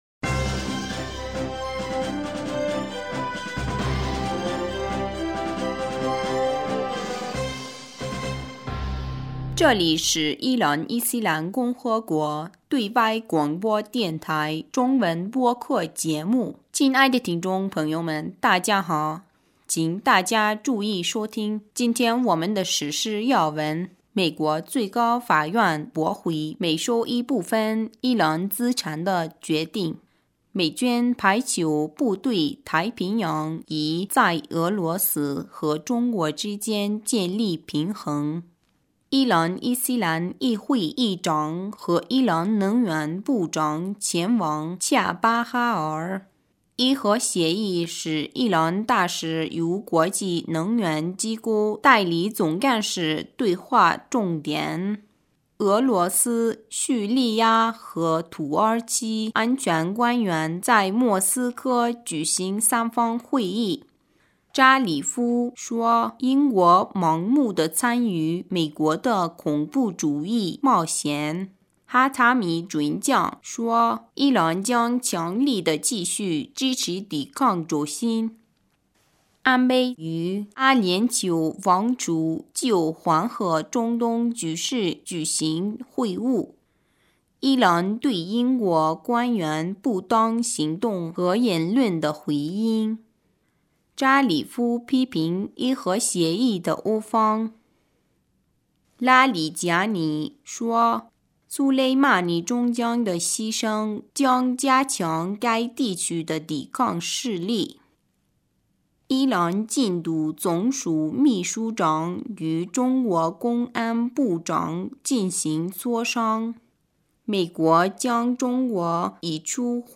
2020年1月14日 新闻